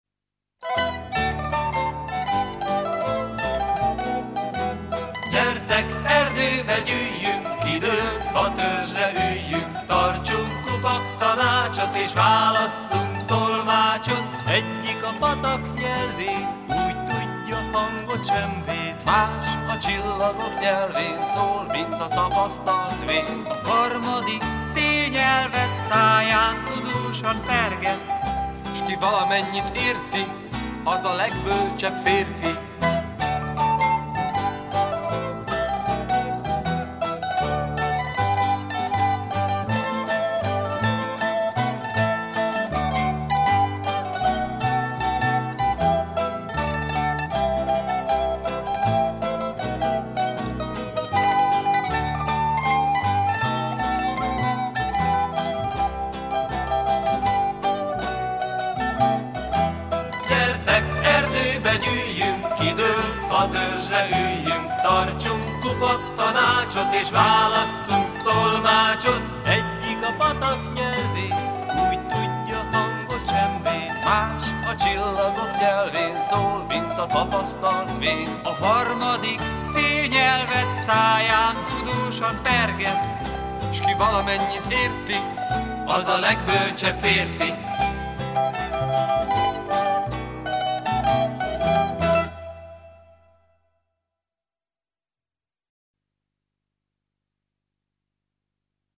Koncert